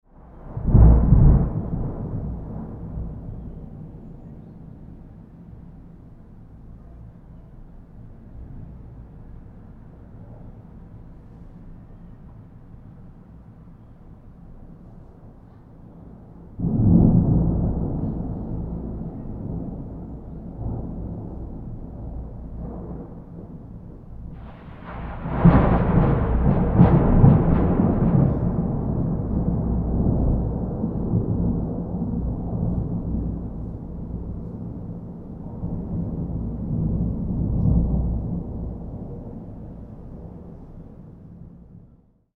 Thunderclap Sound Effect
Description: Thunderclap sound effect. Powerful and intense thunderstorm. A storm is approaching with deep, rumbling thunder sounds.
Thunderclap-sound-effect.mp3